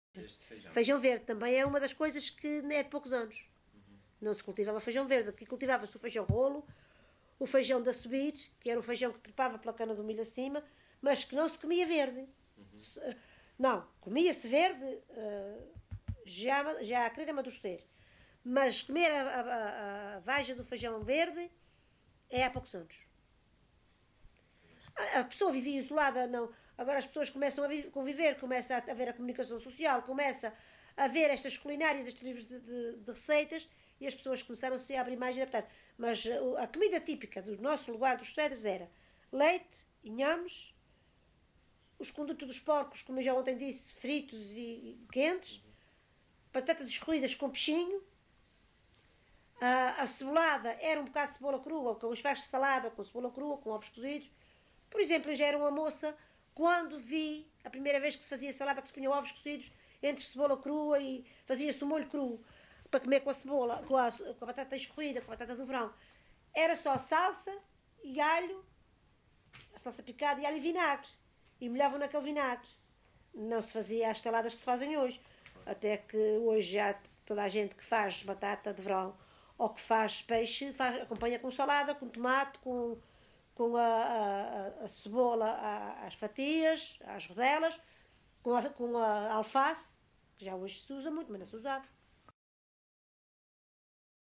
LocalidadeCedros (Horta, Horta)